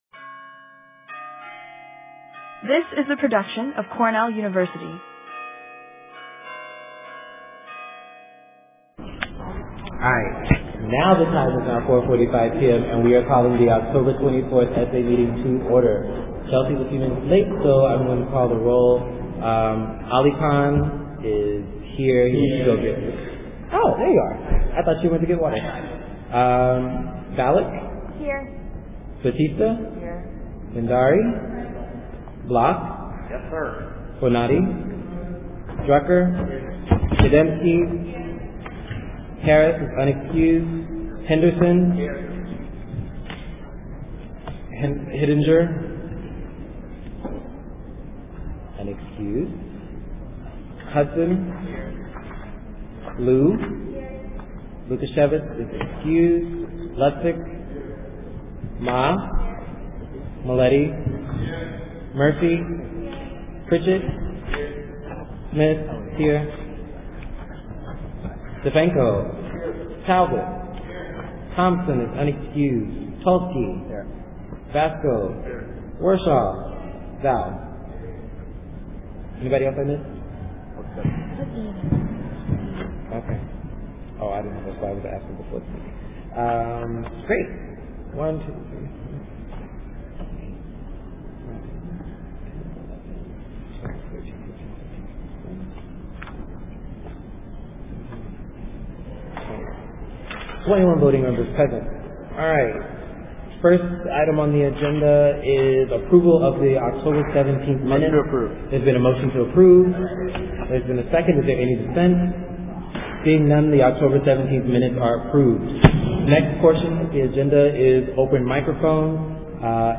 The last 5 minutes are missing due to technical difficulties